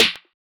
KINDA CLOSE 10,000 DEGREES SNARE.wav